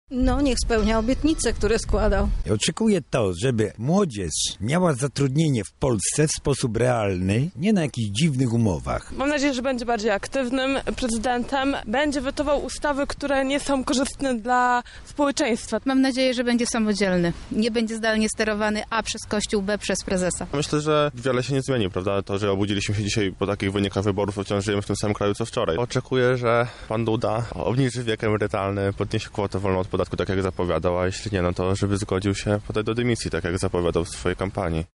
Nasz reporter zapytał czego mieszkańcy Lublina oczekują od nowego prezydenta.